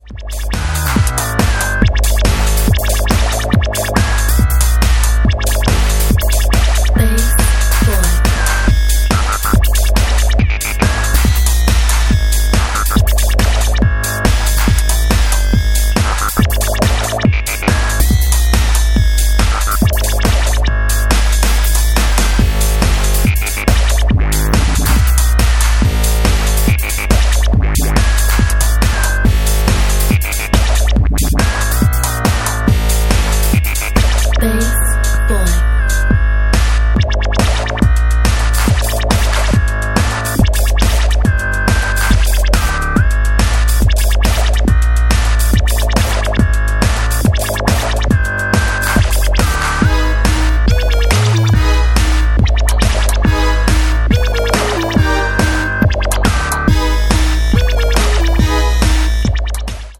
140 bpm